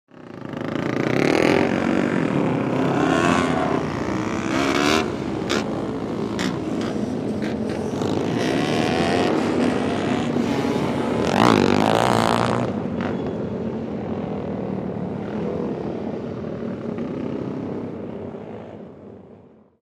Несколько кроссовых мотоциклов ездят по треку
• Категория: Мотоциклы и мопеды
• Качество: Высокое